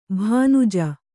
♪ bhānuja